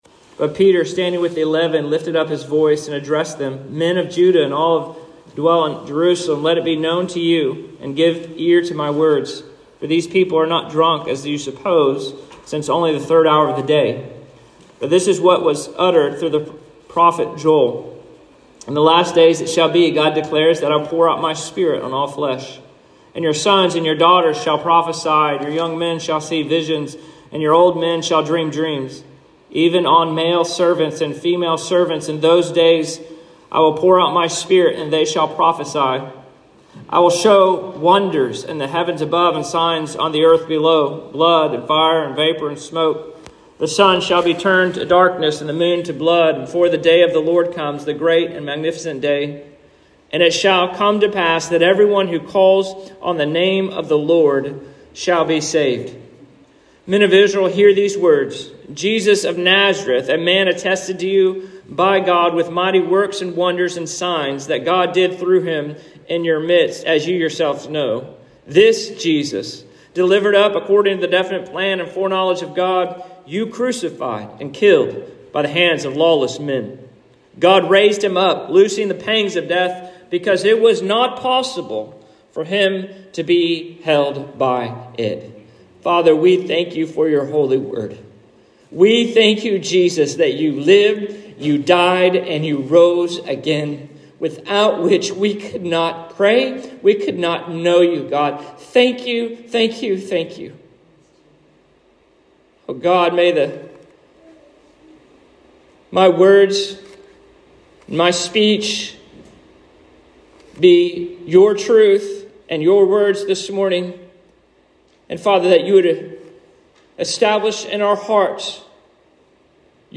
Sermons | Christ Community Church